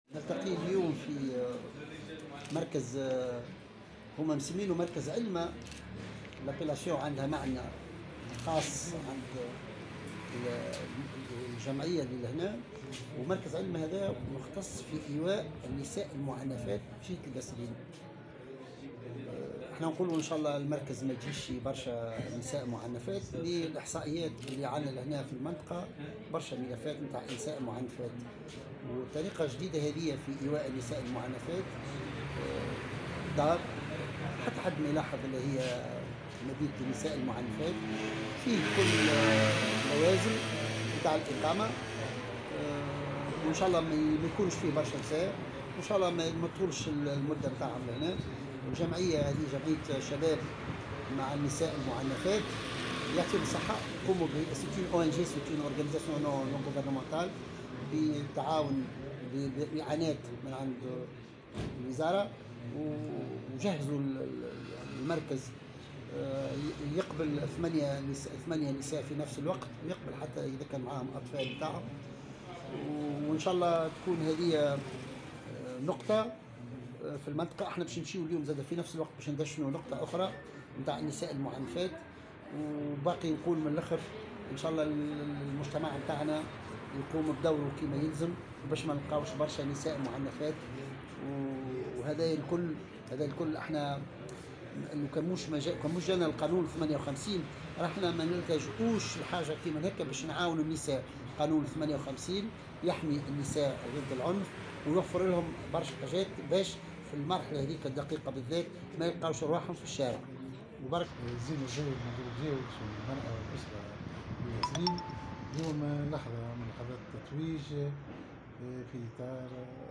القصرين: افتتاح مركز علمة للنساء المعنفات (تصريحات)